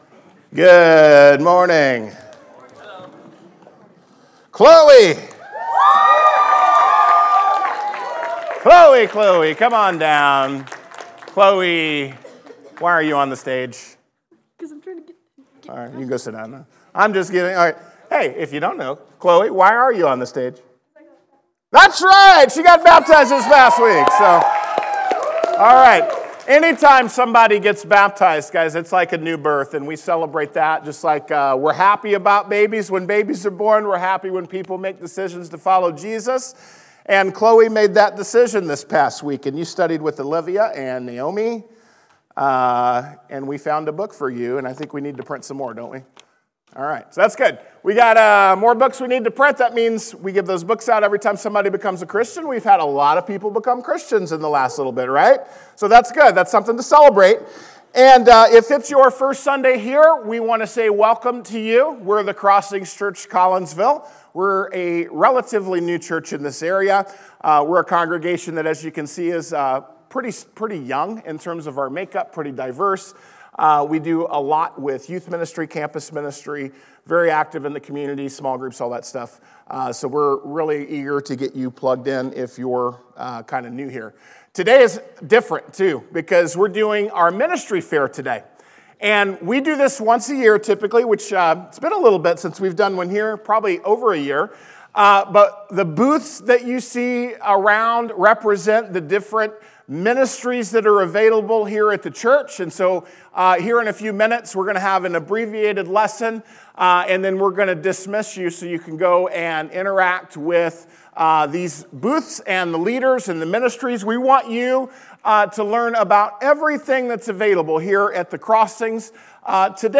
Lesson presented at The Crossings Church Collinsville in Collinsville, IL – a non-denominational church that meets Sunday mornings at 2002 Mall Street, Collinsville, IL just outside of St. Louis.